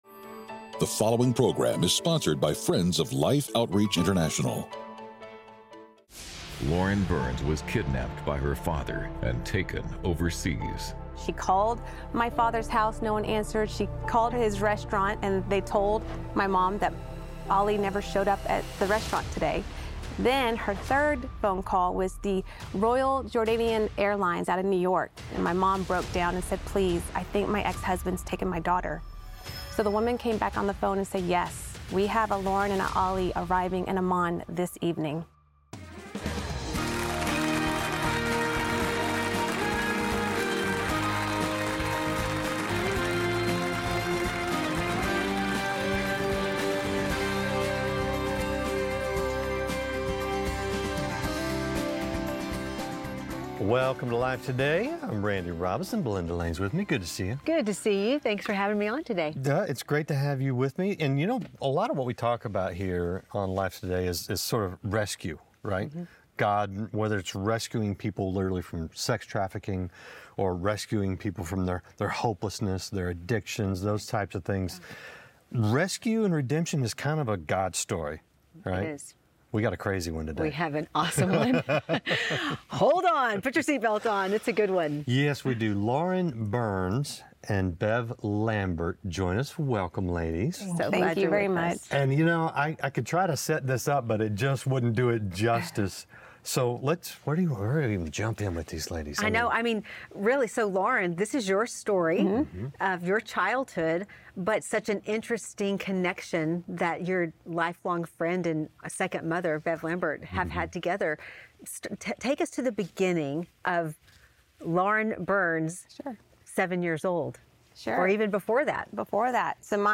A woman who was taken from her mother as a child in order to be raised in a Muslim country tells the emotional story of her abduction.